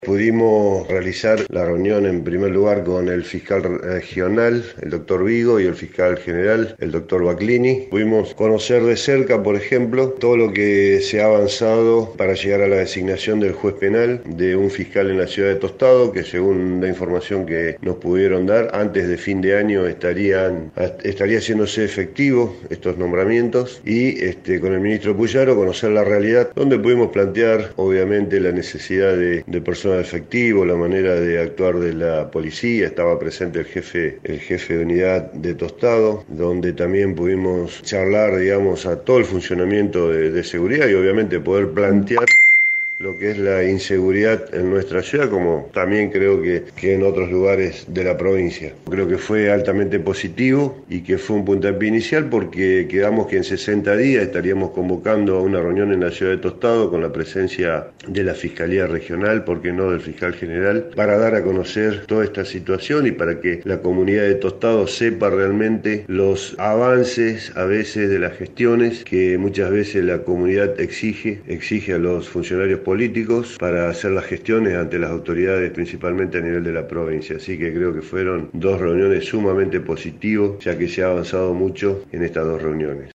El intendente Enrique Mualem brindó más detalles: